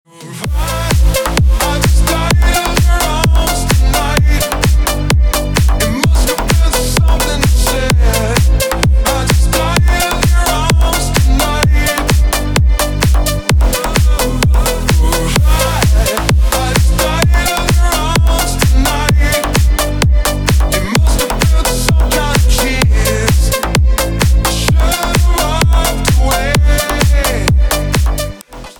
Dance рингтоны